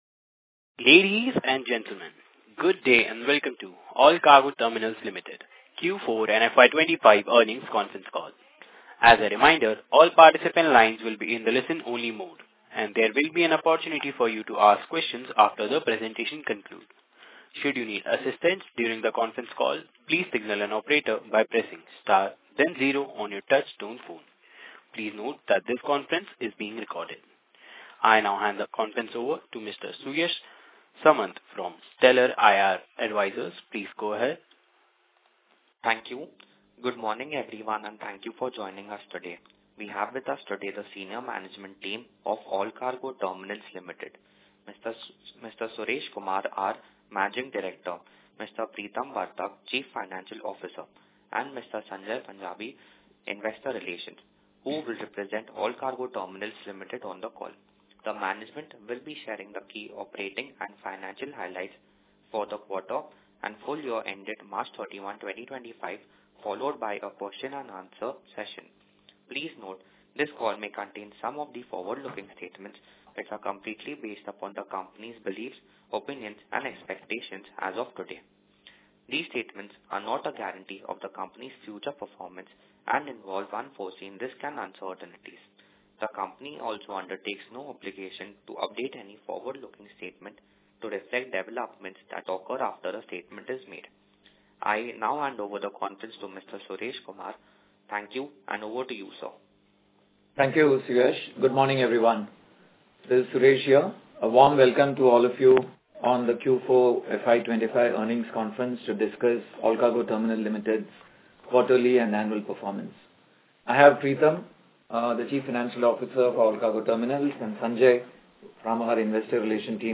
Concalls
Allcargo-Terminals-Q4FY25-Earnings-Call-Audio.mp3